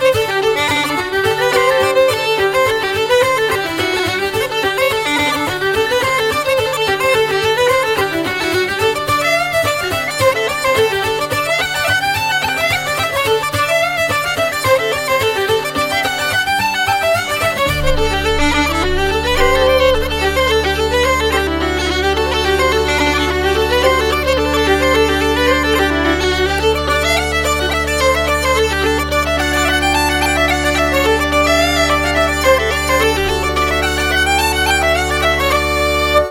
fiddle, Hardanger d’amore
uilleann pipes, flute and whistles
bouzouki and vocals